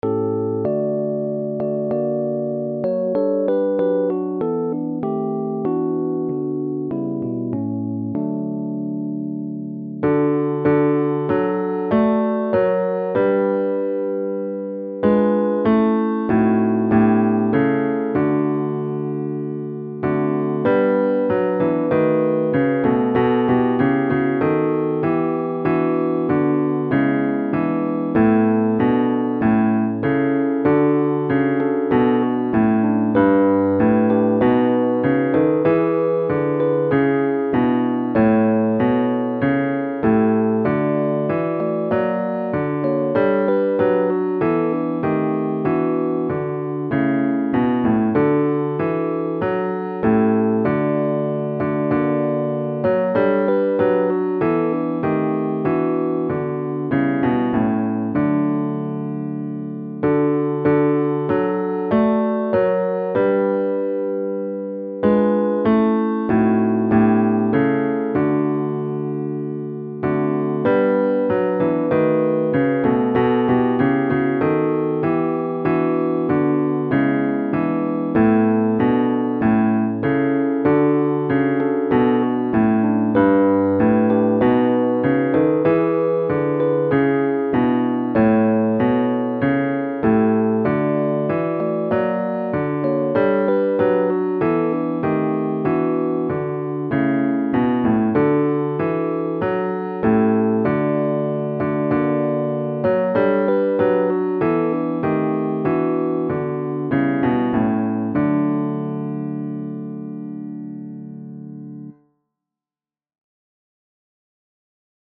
Bass-GAMP-Alma-Mater.mp3